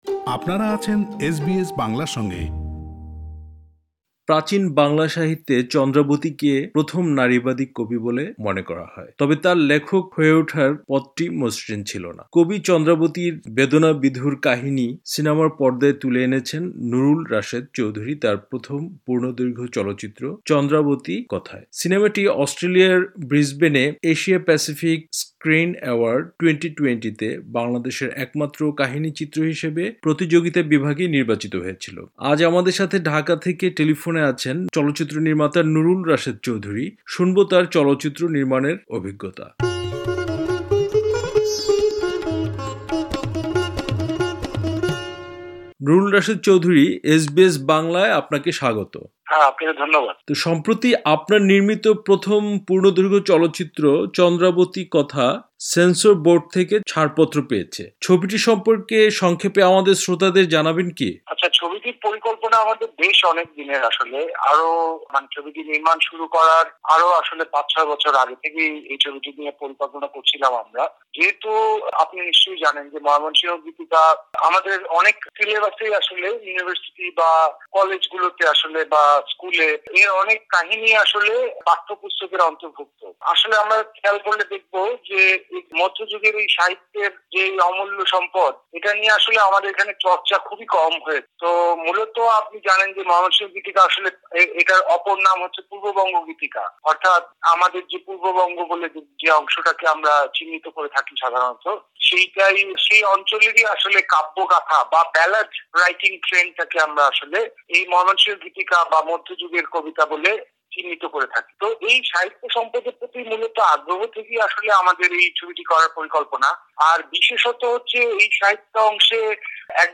তিনি ঢাকা থেকে টেলিফোনে কথা বলেছেন এসবিএস বাংলার সাথে, ব্যক্ত করেছেন তার চলচ্চিত্র নির্মাণের অভিজ্ঞতার কথা।